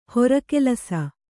♪ hora kelaa